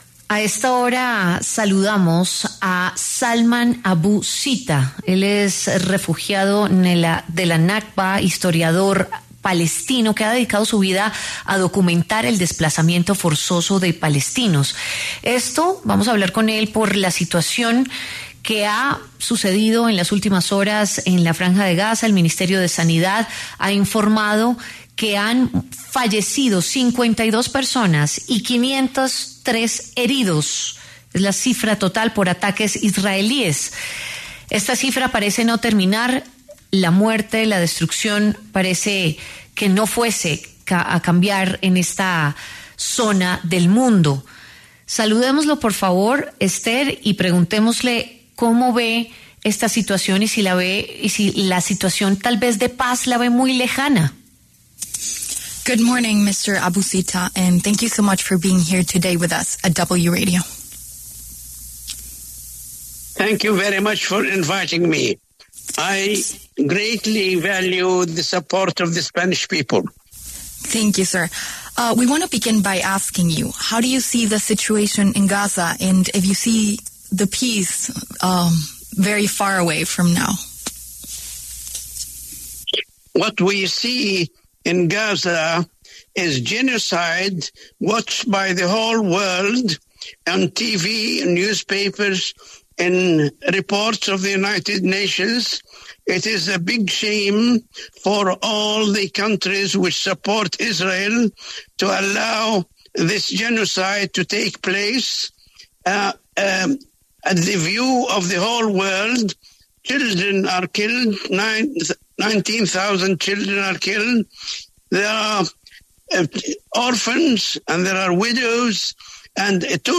Salman Abu Sitta, refugiado de la Nakba e historiador palestino, habló en La W sobre la situación de los desplazados en la Franja de Gaza y elevó un llamado de atención a la comunidad internacional.